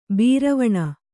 ♪ bīravaṇa